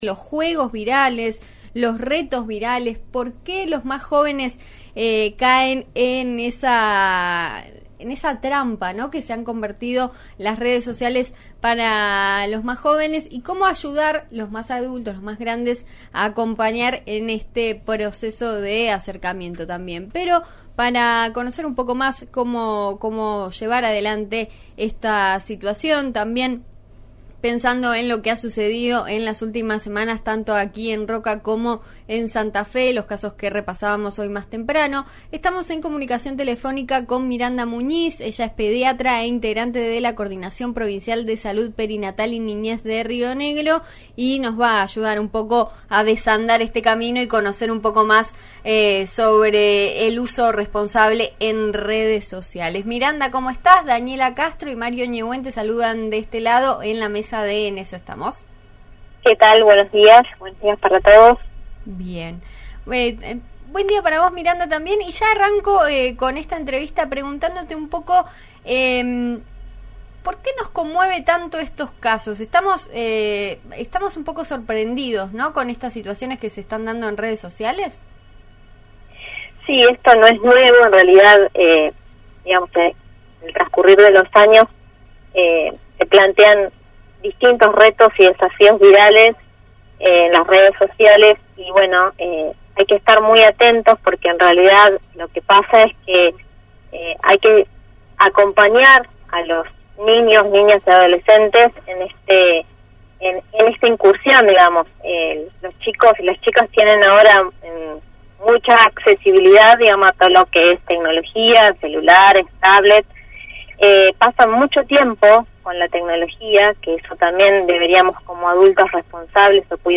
En diálogo con RIO NEGRO Radio